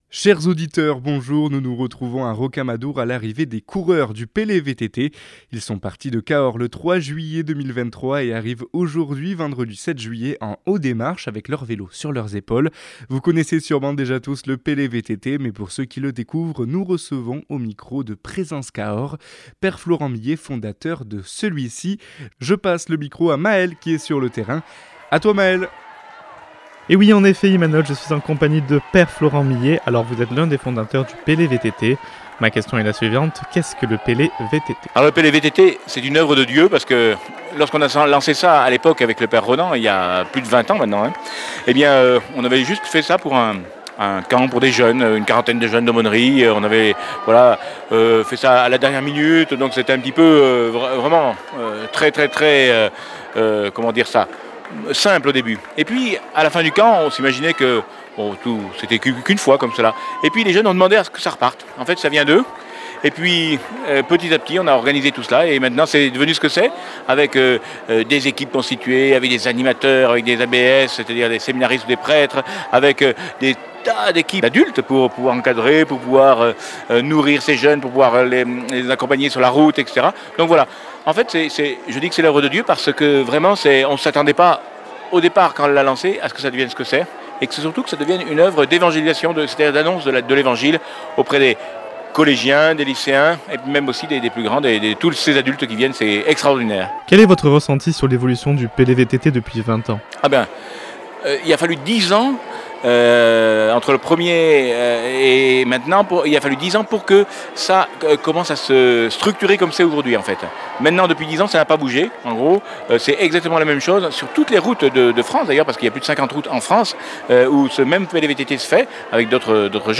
Radio Présence Cahors était à l'arrivé du PELE VTT 2023 pour un reportage exclusif.